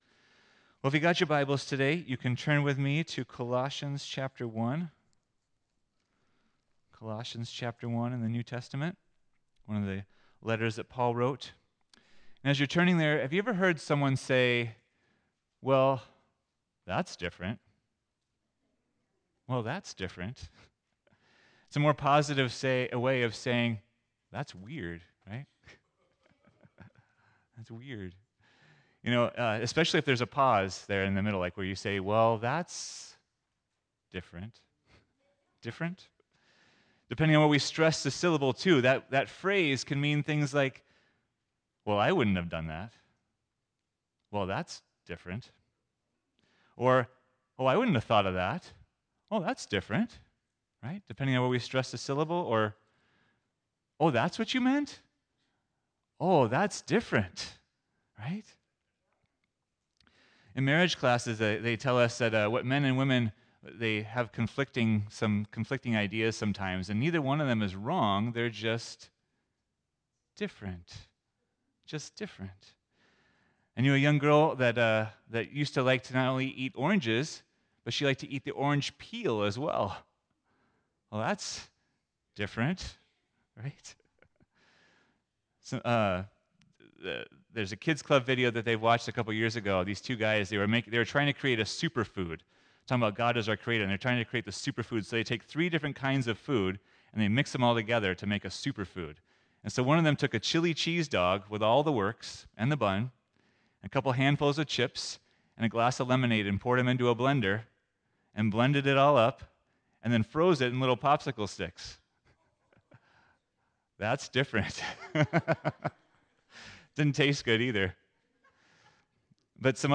What in the world do these have to do with a sermon?!